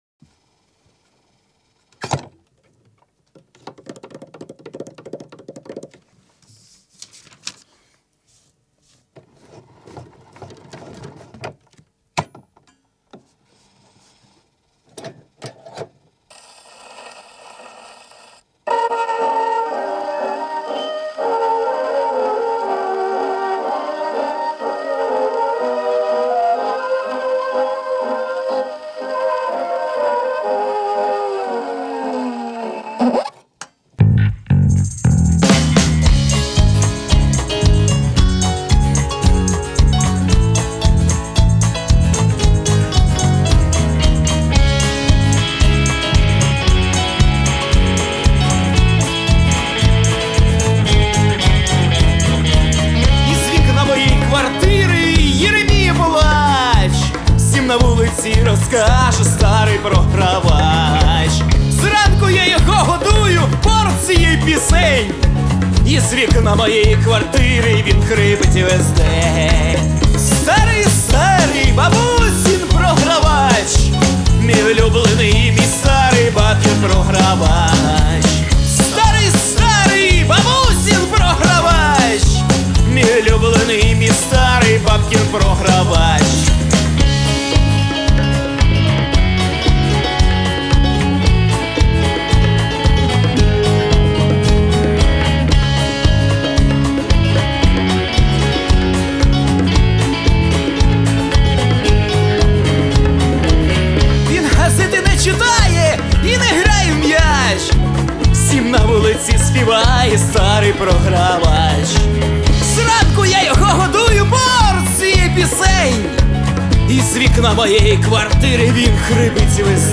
Любители Rock`N`Rolla.